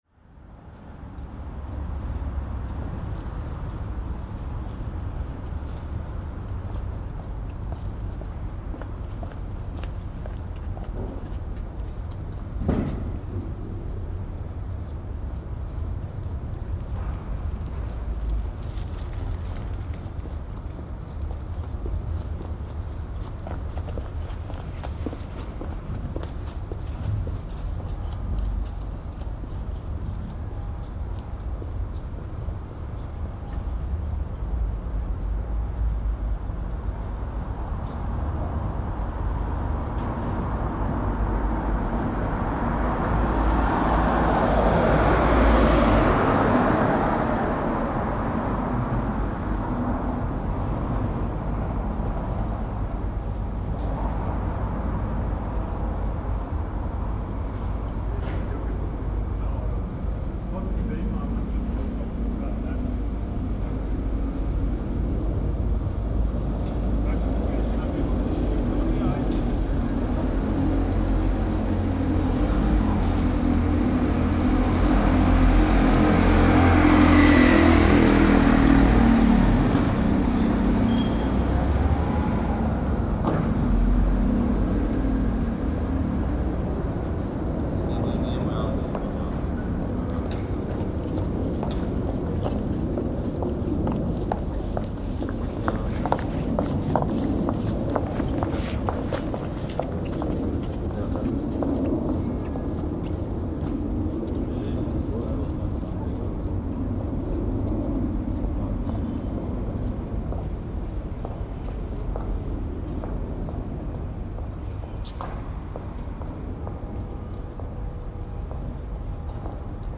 A Spring sunny day 2014, not that much traffic, people walking for lunch.